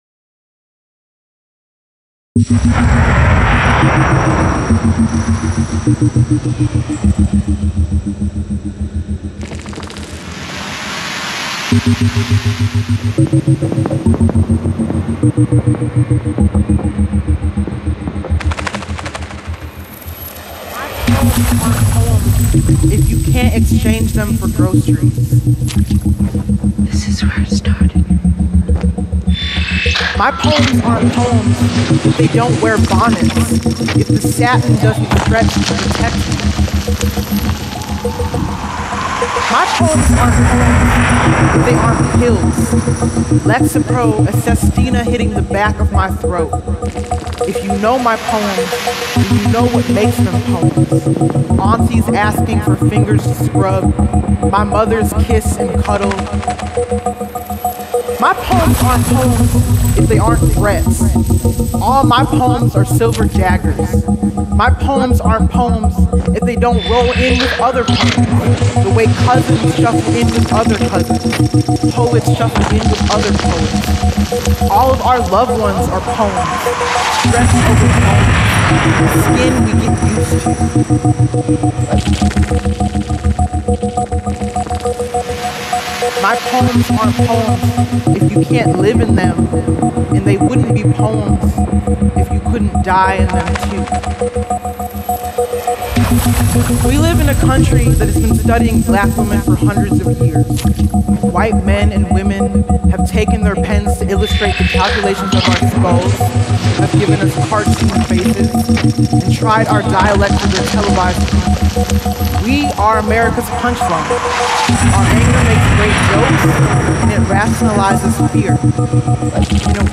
It’s an audio collage made from snippets of films, songs, speech and her own voice.